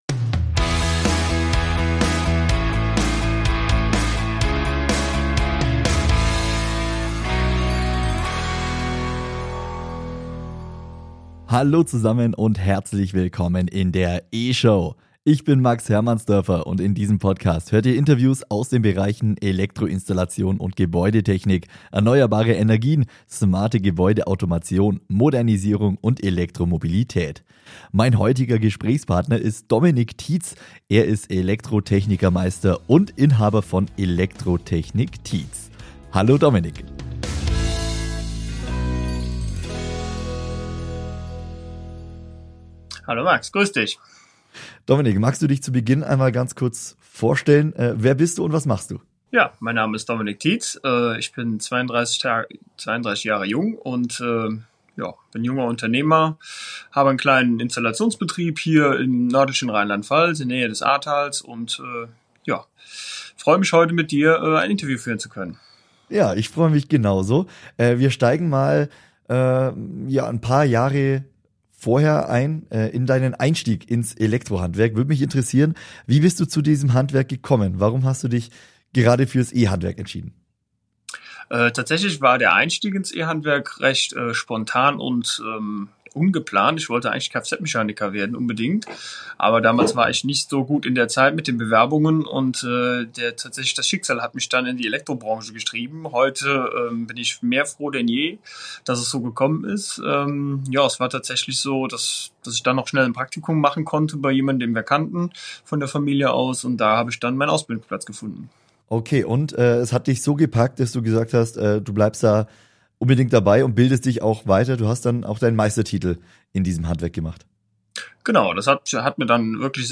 Heute im Interview